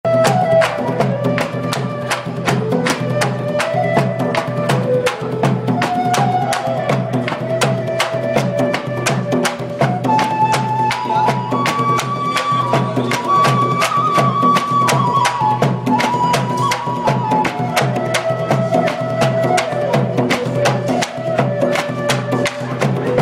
percussions à Pune
à Pune ; percussion (avec bambous) avec danse acrobatique